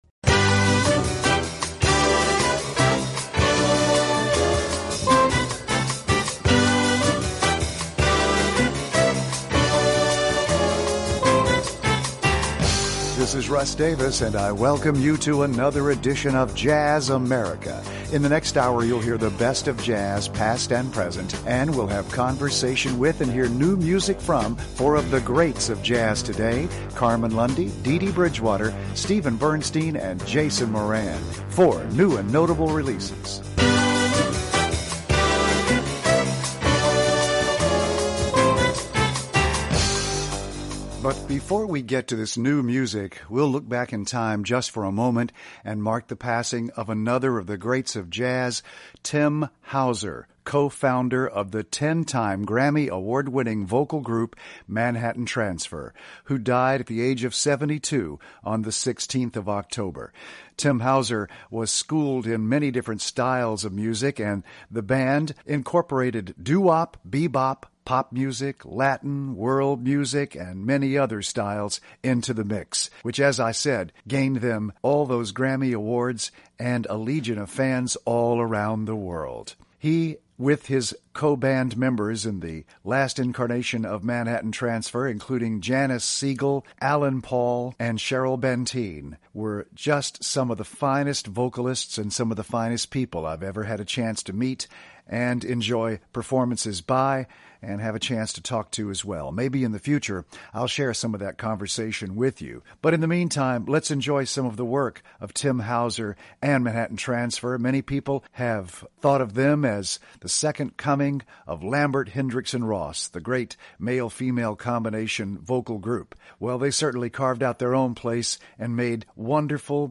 brings you the best in jazz, present and past